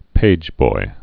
(pājboi)